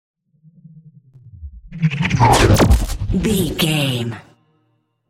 Sci fi hit technology electricity
Sound Effects
heavy
intense
dark
aggressive
hits